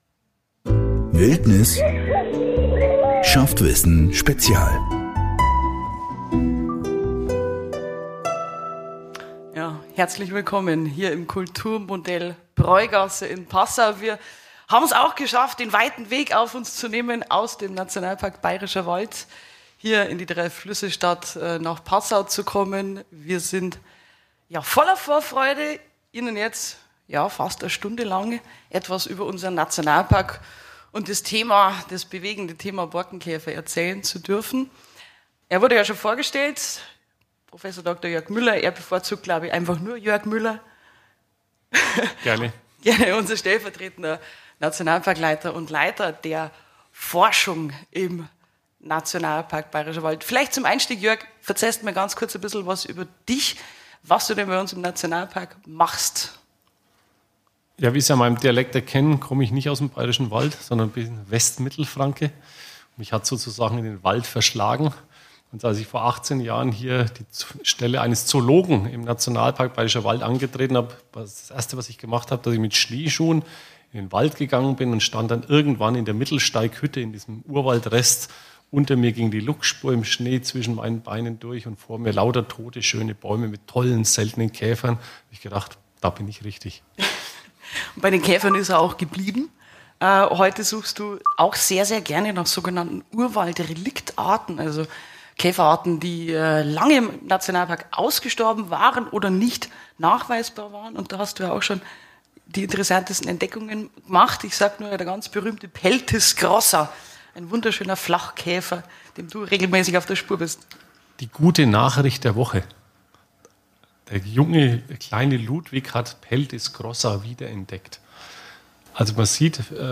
Zum Abschluss der Reihe gibt es eine Sonderfolge, live aufgenommen beim ersten Podcastfestival in Passau.